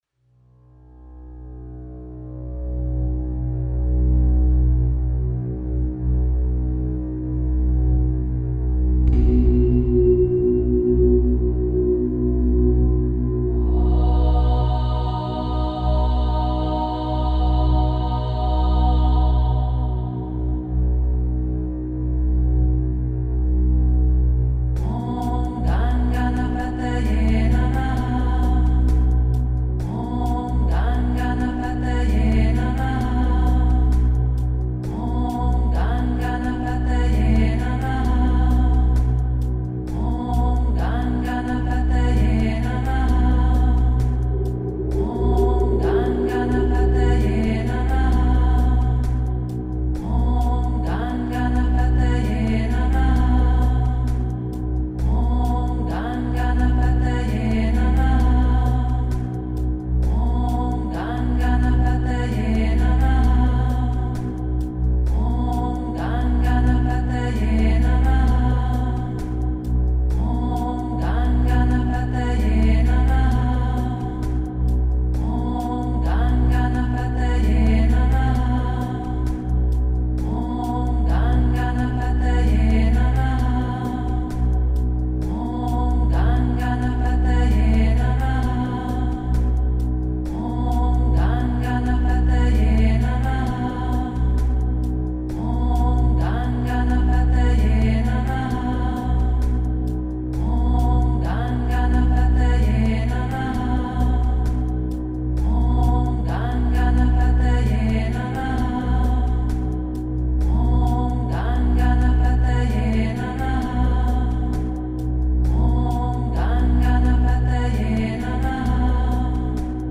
La force des Mantras (Format PDF et audio MP3 – 11:07) Le mantra peut être récité à voix haute, prononcé de façon silencieuse et intérieure pour soi, ou encore simplement écouté. Les mantras sont récités en cycle de 9 à 108 répétitions pour honorer le pouvoir de la tradition, 108 étant un chiffre sacré en Inde et aussi dans diverses traditions du monde.